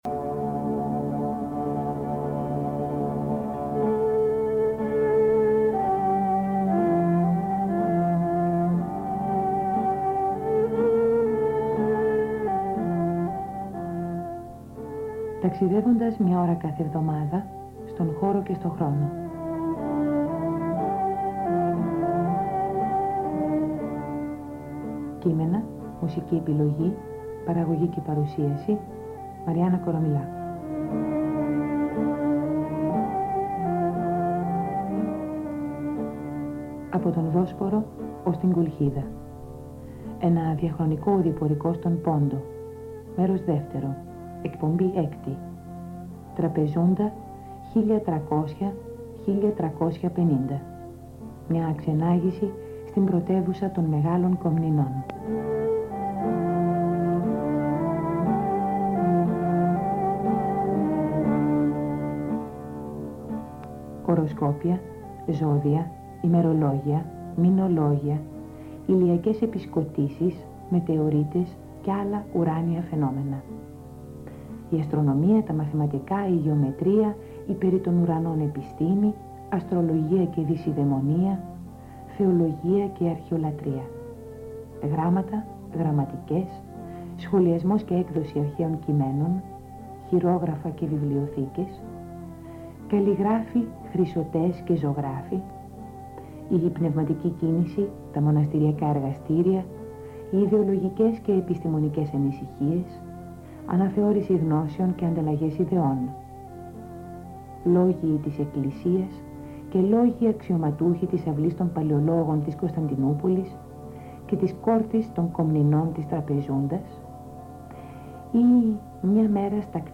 Υπότιτλος Ταξιδεύοντας στον χώρο και τον χρόνο Είδος Audio / Ακουστικό Χαρακτηρισμός Χαρακτηρισμός Ραδιοφωνική εκπομπή.